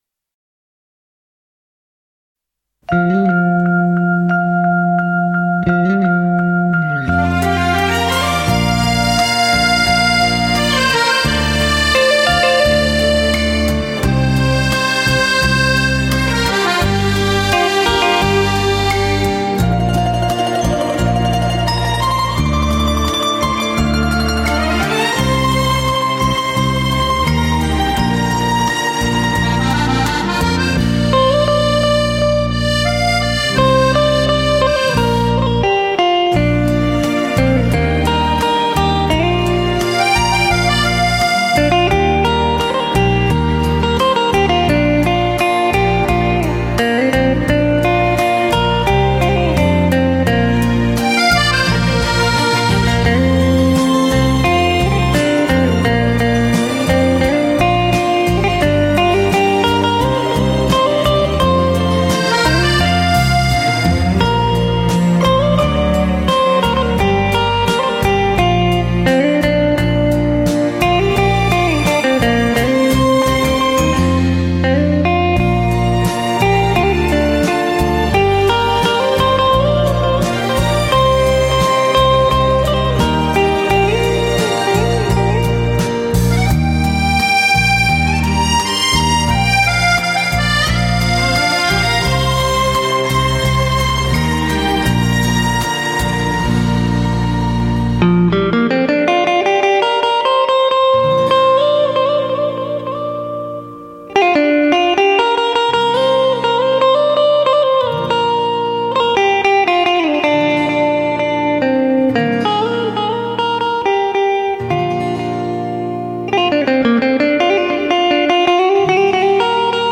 音乐类型:试音碟
曲曲震撼，令人心旷神怡的吉他轻音乐作品。
分析力提高/音色更加平衡/失真少/高频细腻/中频圆润/低频亮感十足/动态提升/层次清晰